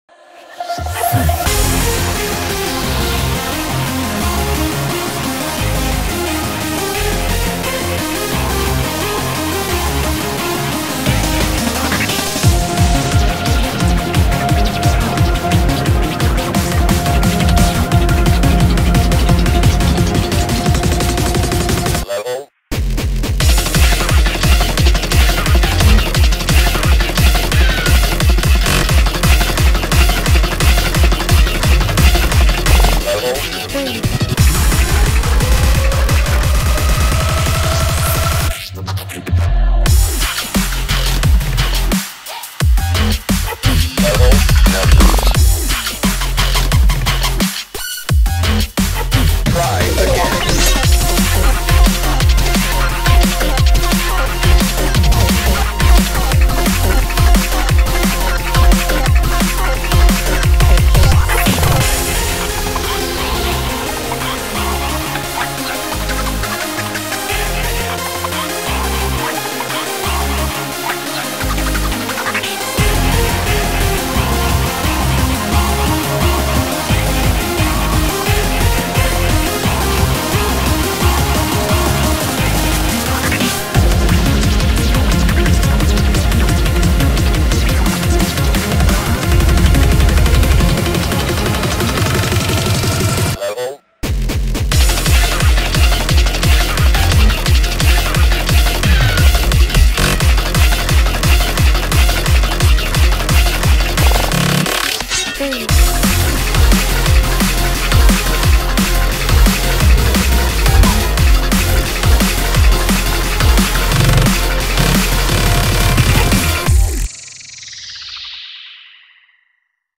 BPM88-175
Comments[DRUMSTEP / DnB]